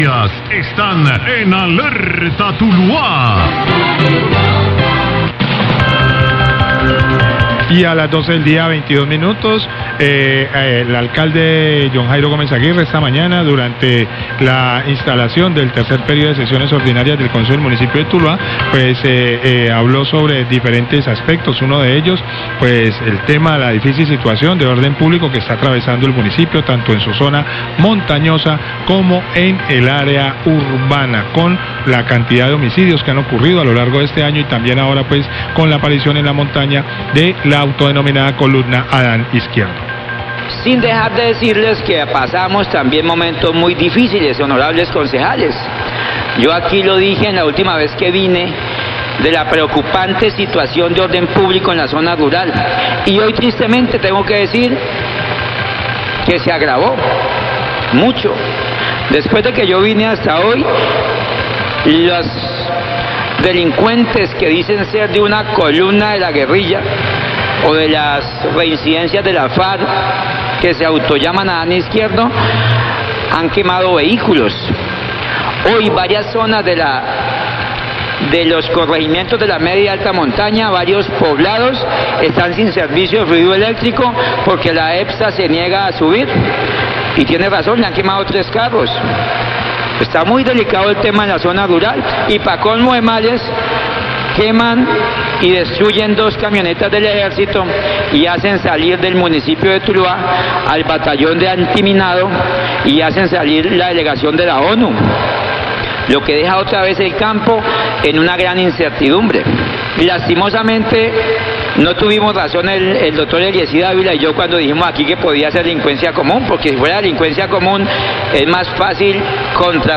Alcalde de Tuluá habla sobre la violencia que vive la zona rural y la necesidad de seguir trabajando por ella, La Cariñosa, Fm, 1220pm
Radio